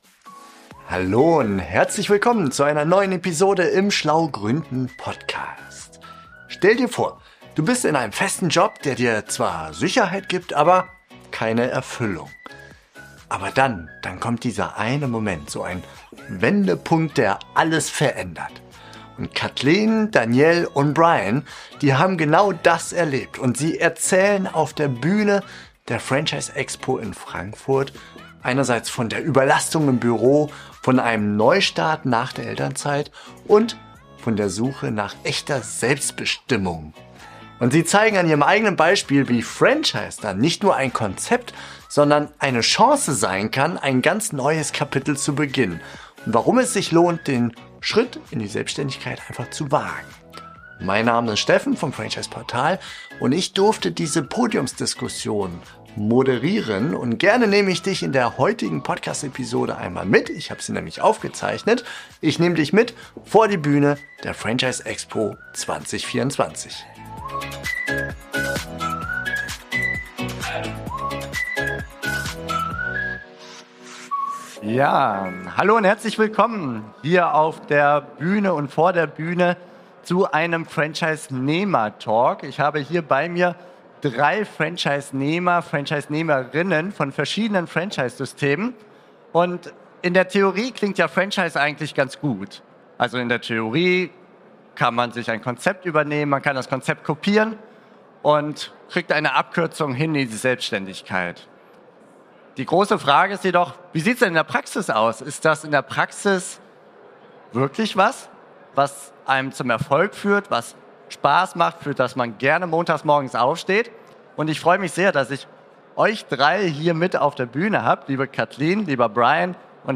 In dieser Episode teilen die drei ihre ganz persönlichen Geschichten, aufgezeichnet während einer spannenden Podiumsdiskussion auf der Franchise Expo (FEX) in Frankfurt: